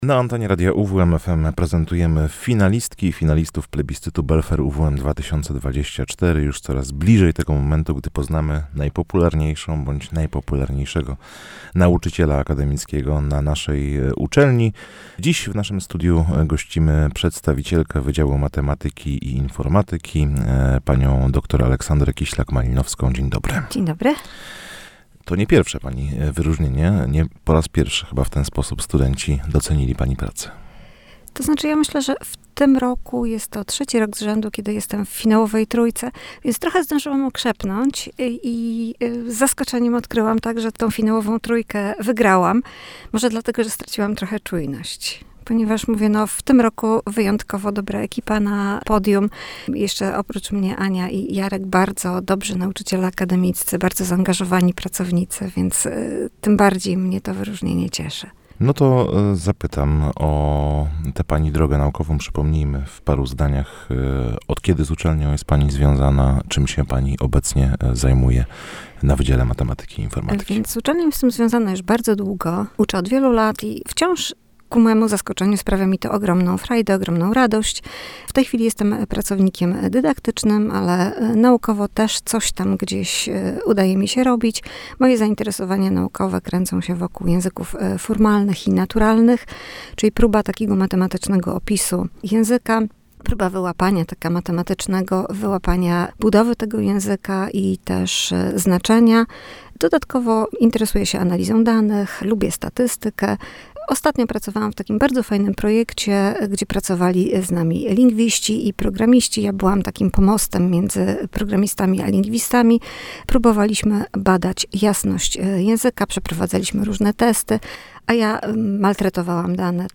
– mówiła w naszym studiu.